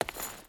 Stone Chain Walk 2.wav